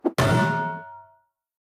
Звуки мультяшные
Звук удара гонга в анимации